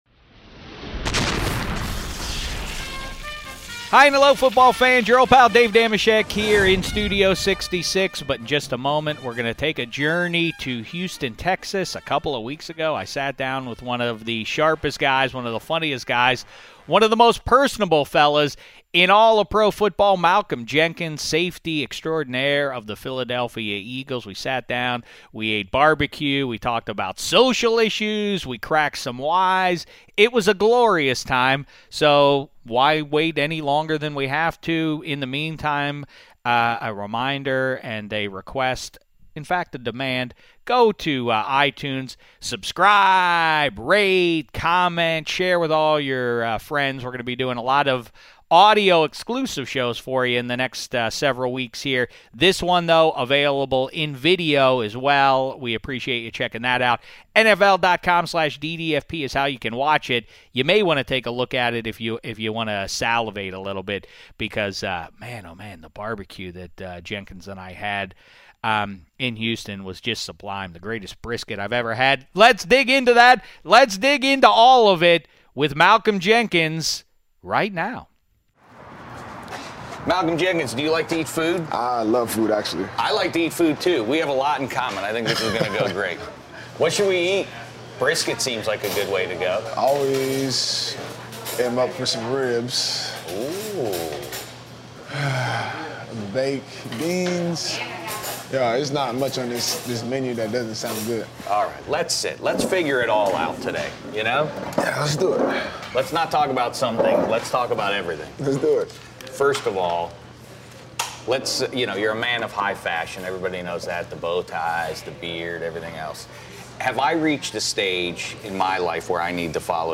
Dave Dameshek sits down with Philadelphia Eagles safety Malcolm Jenkins at Gatlin's BBQ in Houston, Texas to discuss if New Orleans has better food than Philadelphia, as well as Malcolm's limited experience with tailgating football games.